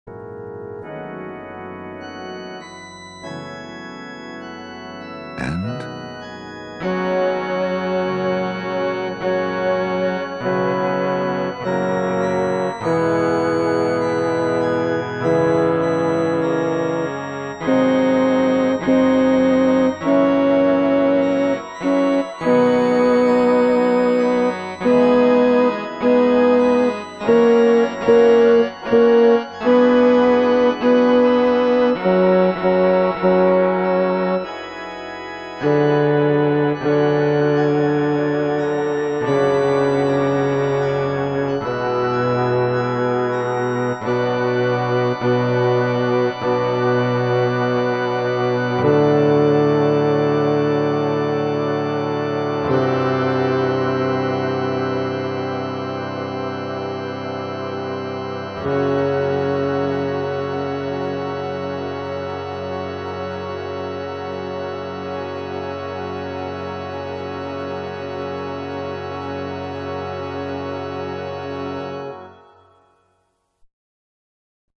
There is no text, just your part.
In Paradisum-begins at square 101 (1st Bass)